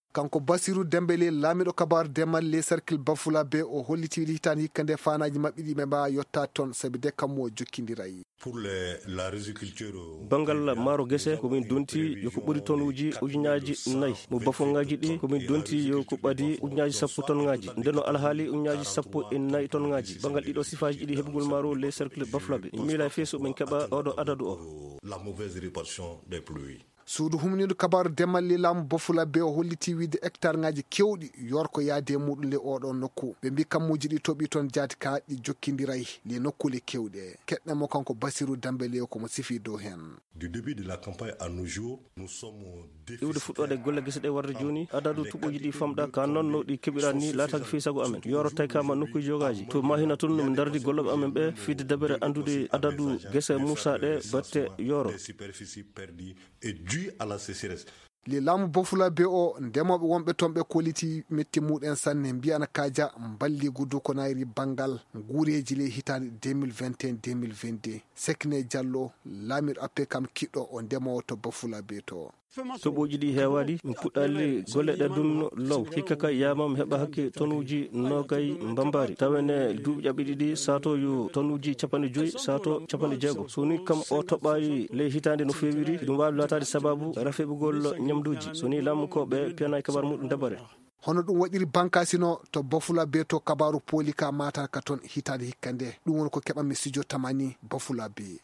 Ce reportage a été réalisé en octobre dernier […]